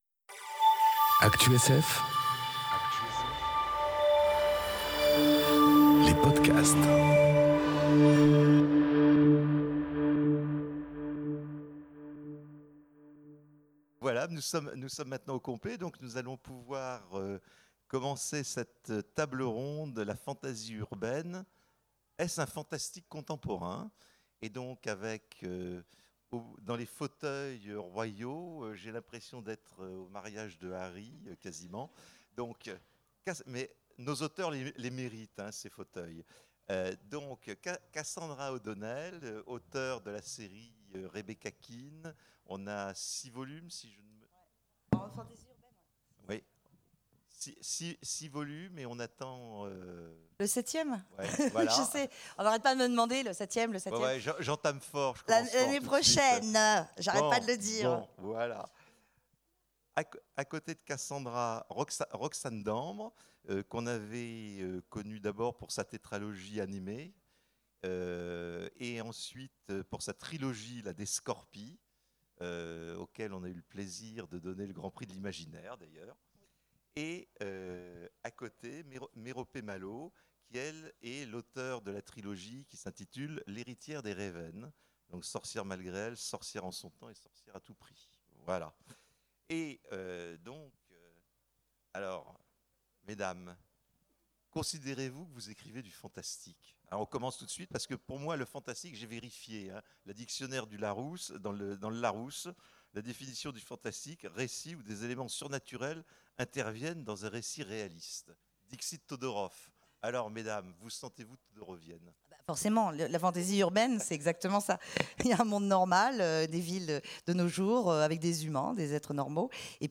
Conférence La fantasy urbaine... Un fantastique contemporain enregistrée aux Imaginales 2018